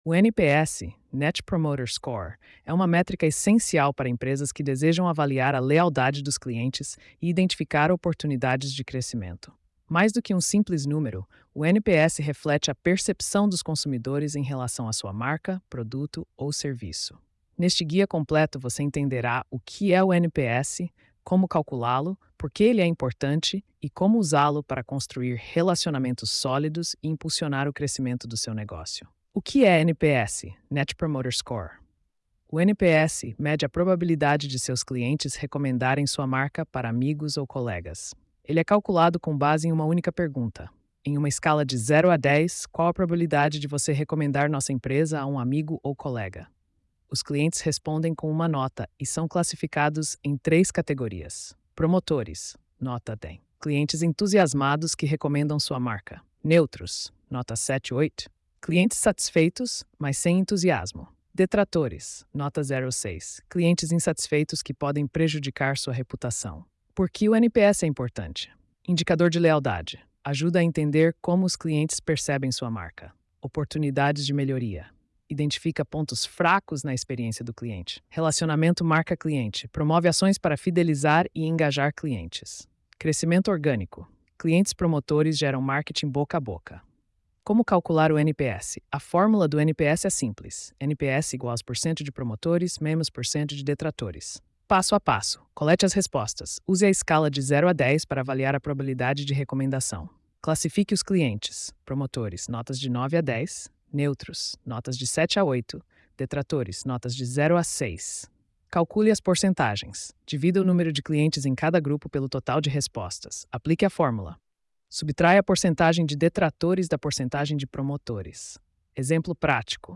post-2622-tts.mp3